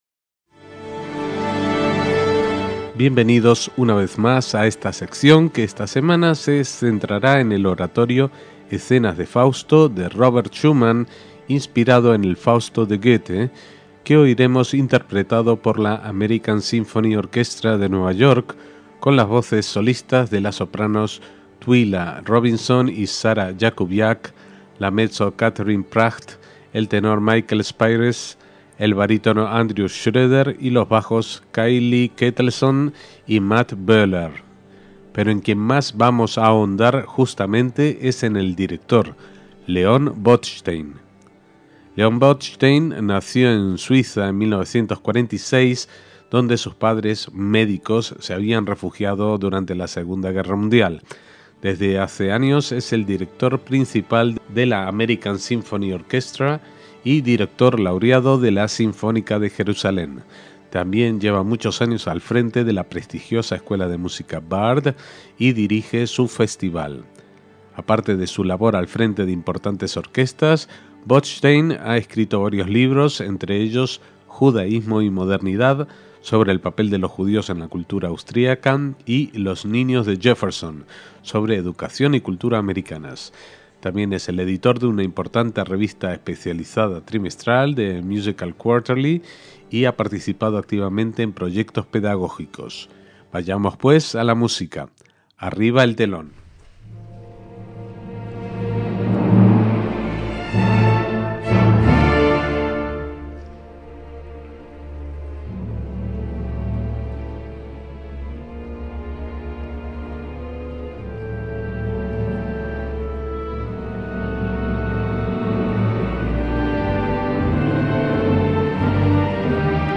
Oratorio "Escenas de Fausto", de Robert Schumann, dirigido por Leon Botstein